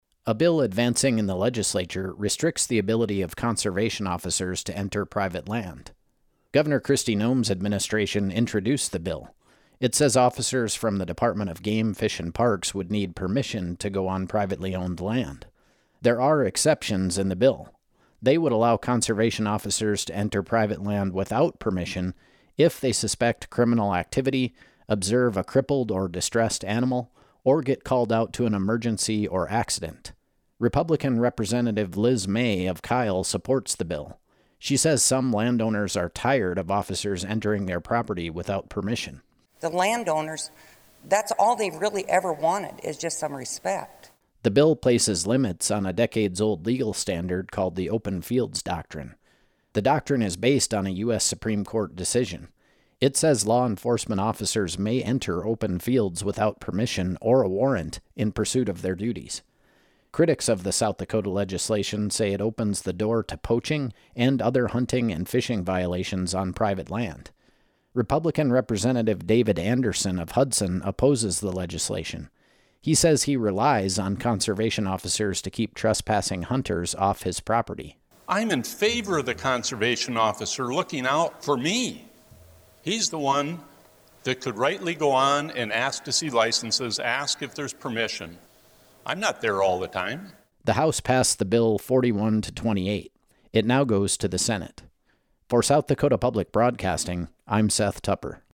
The House vote on HB 1140.